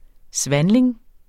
Udtale [ ˈsvæːnleŋ ]